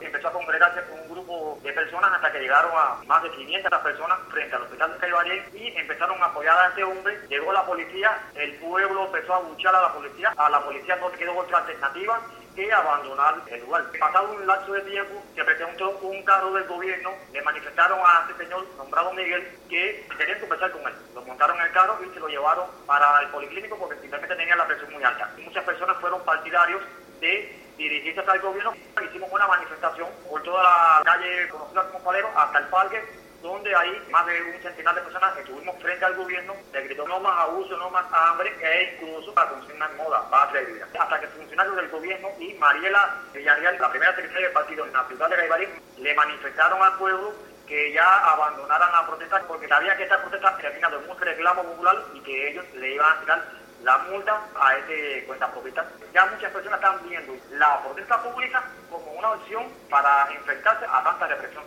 Las declaraciones del periodista